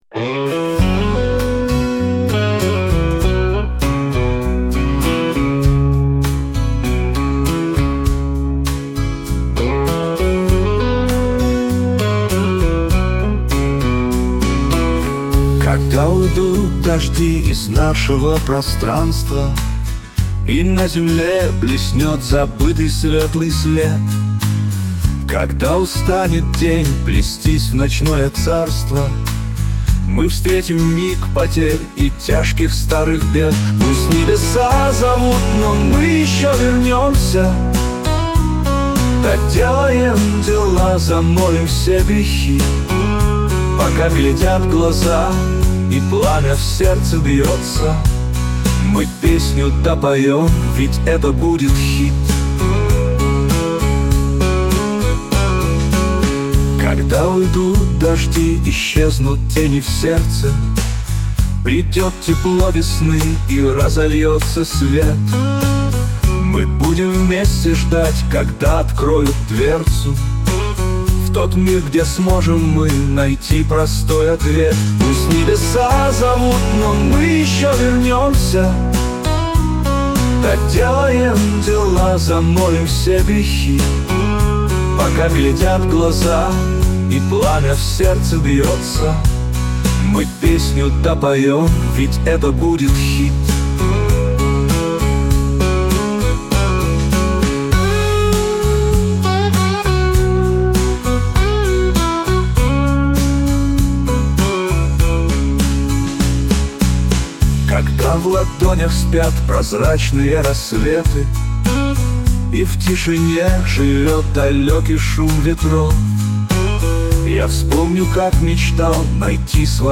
Всё написано и сыграно так, чтобы это можно было играть и петь под акустические гитары в компании, всё несложно и предельно доходчиво. Даже мелодические соло сыграны так, чтобы их мог освоить начинающий музыкант (между прочим, понимание благотворности такой простоты — главное, что оставил русскому року Виктор Цой).
Также при создании песни использовался музыкальный генеративный ИИ Suno AI, но в целом песня скомпонована из кусочков и живого звука